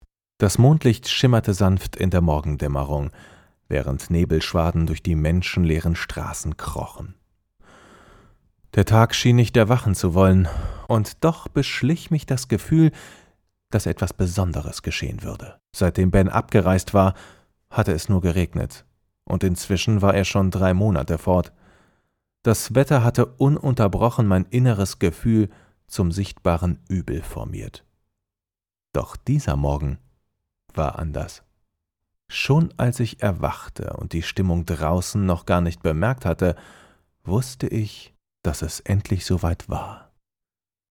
deutscher Sprecher, off Sprecher, TV Radio Moderator, Werbesprecher, Trailer, Hörbuch, Doku, Videospiele, div.
Sprechprobe: Sonstiges (Muttersprache):